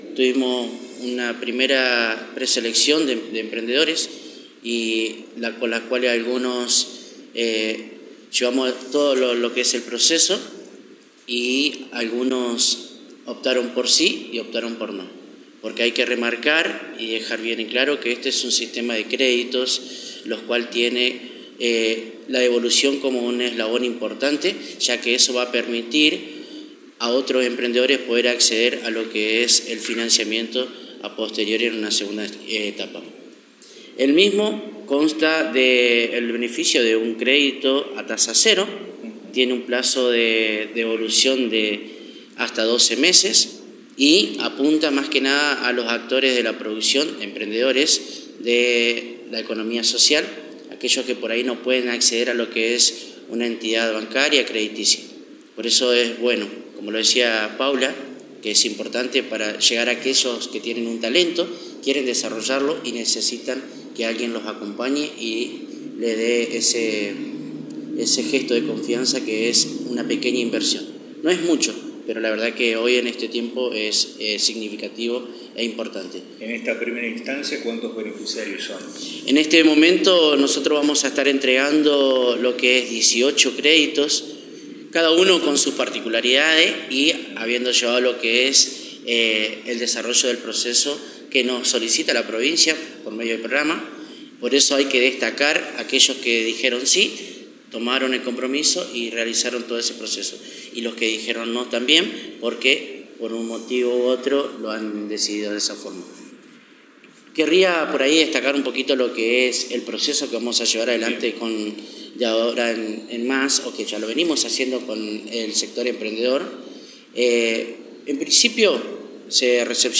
Ricardo Saavedra – Subsecretario de Producción del municipio